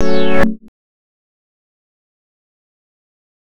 hover.wav